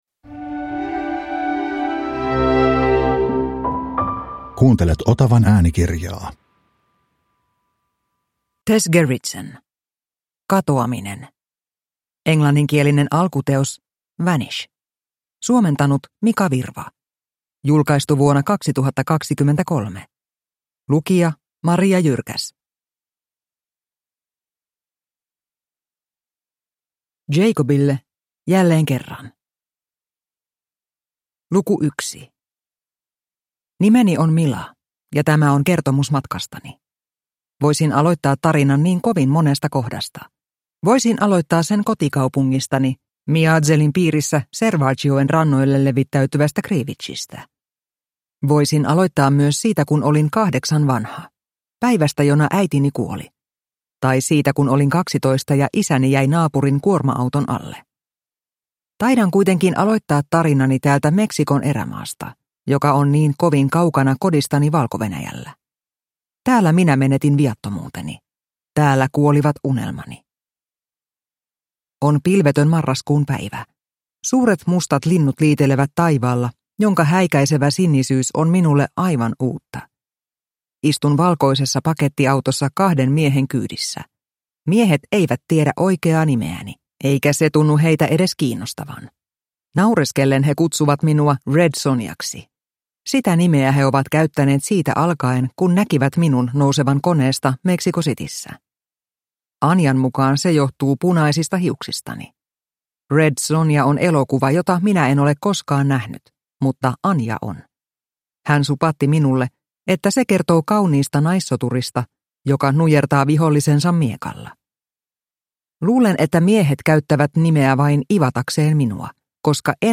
Katoaminen – Ljudbok – Laddas ner